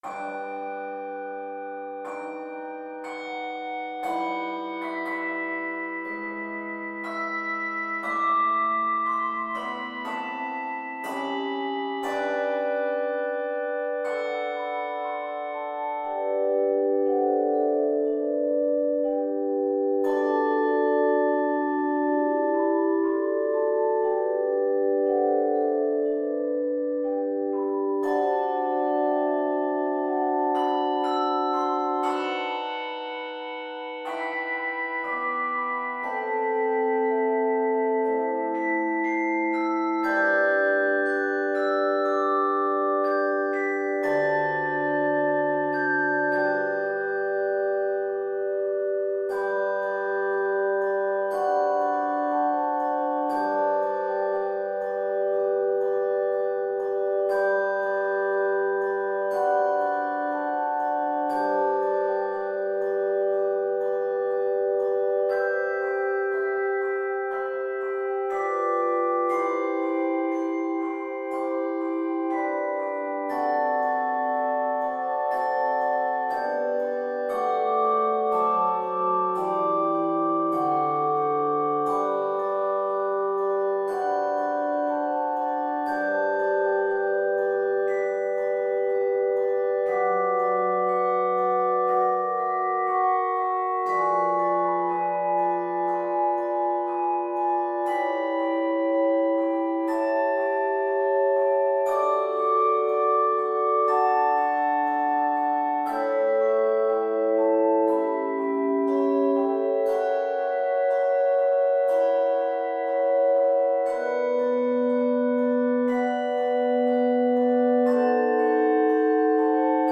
Key of G Major. 61 measures.